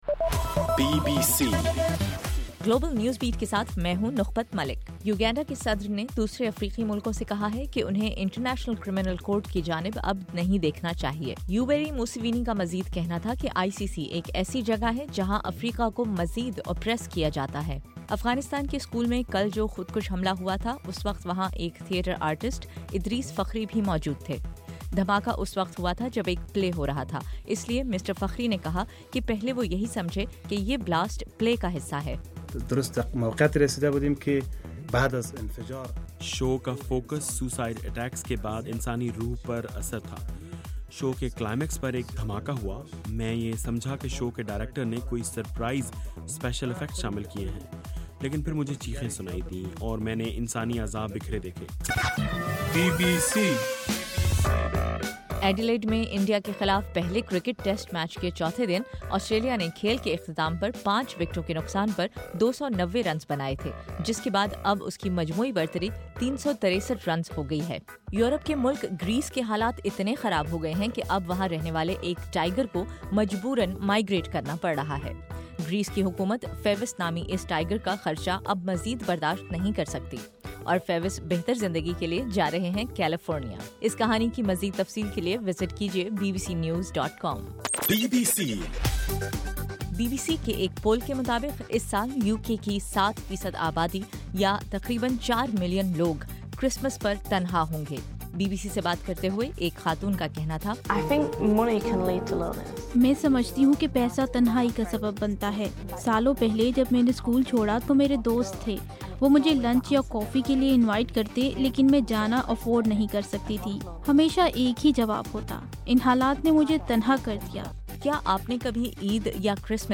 دسمبر 12: رات 11 بجے کا گلوبل نیوز بیٹ بُلیٹن